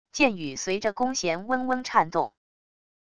箭雨随着弓弦嗡嗡颤动wav音频